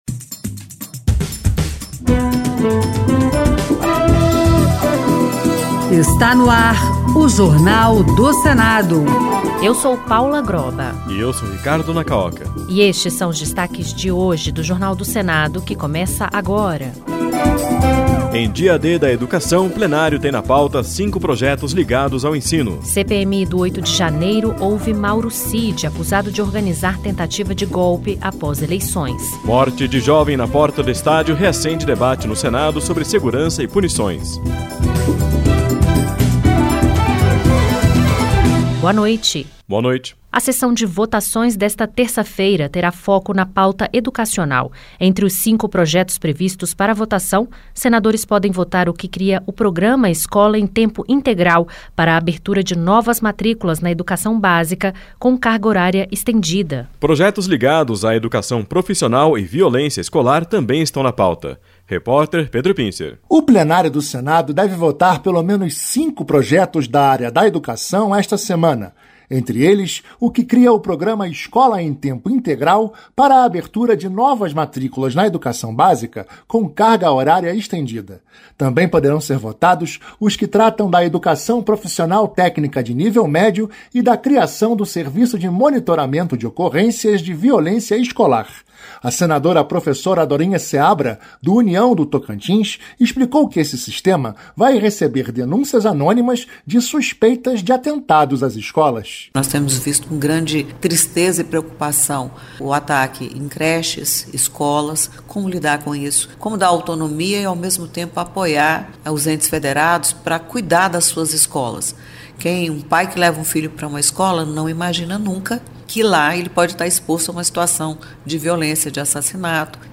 Rádio Senado - Ao Vivo